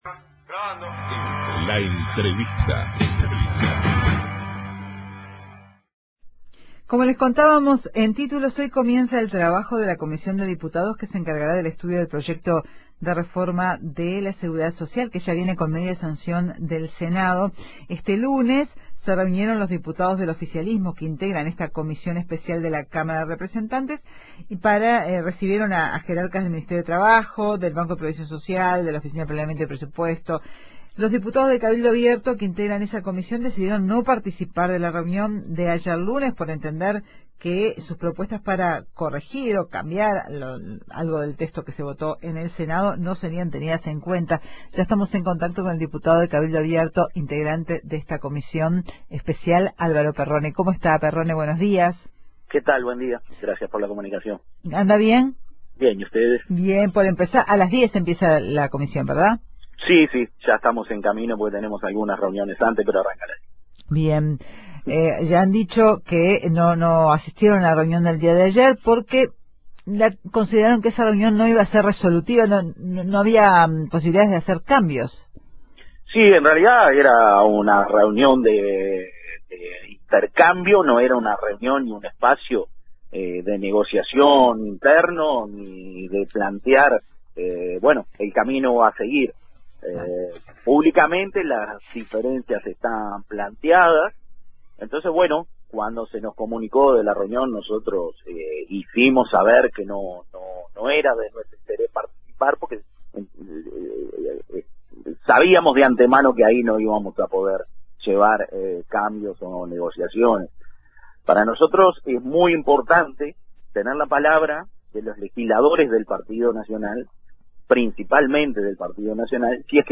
El diputado de Cabildo Abierto Álvaro Perrone dijo a Informativo Uruguay que espera que en el trabajo, que comienza hoy, de la comisión parlamentaria que estudia el proyecto de reforma de la seguridad social, se puedan llevar adelante algunas modificaciones.